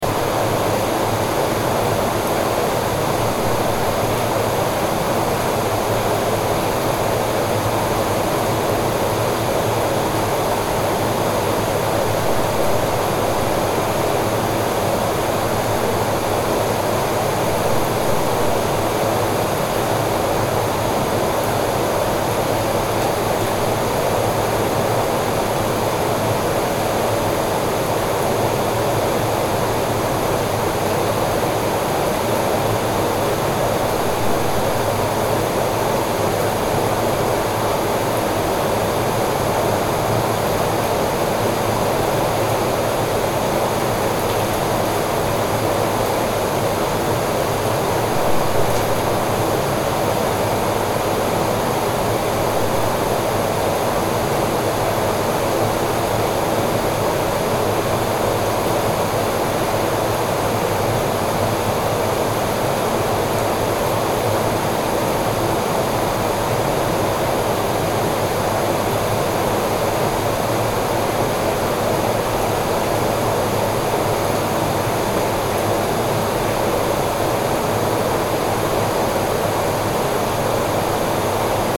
Jacuzzi Sound Effect
Water bubbles in a Jacuzzi as the massager runs. Relaxing murmur from a wellness center. Continuous water sounds. Audio loop.
Jacuzzi-sound-effect.mp3